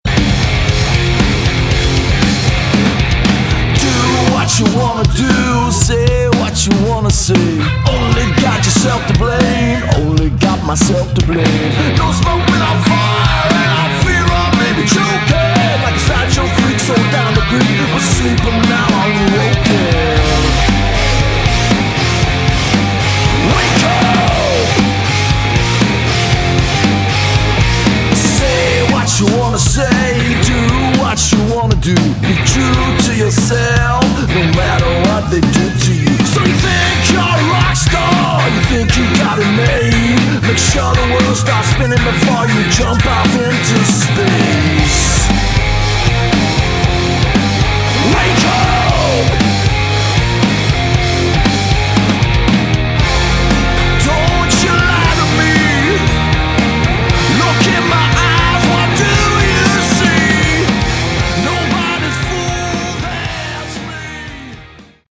Category: Hard Rock
lead vocals, bass
guitar, piano, backing vocals
drums, vocals